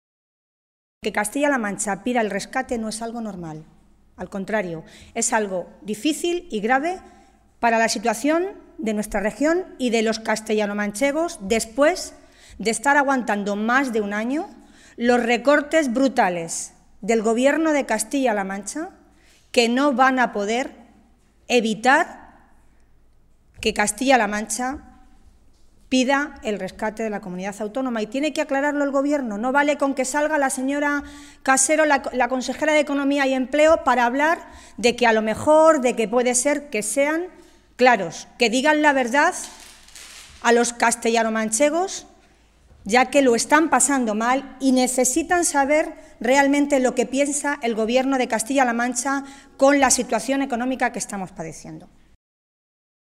Milagros Tolón, portavoz de Empleo del Grupo Parlamentario Socialista
Cortes de audio de la rueda de prensa